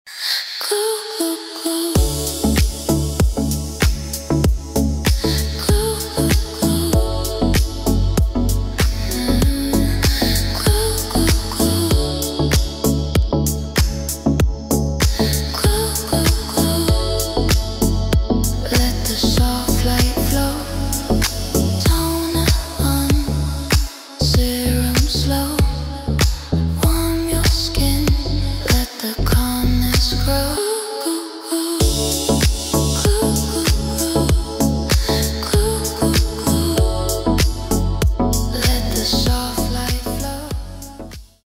спокойные , deep house , приятные , chill house , женские